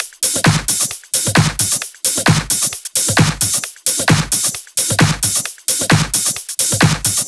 VFH3 132BPM Elemental Kit 4.wav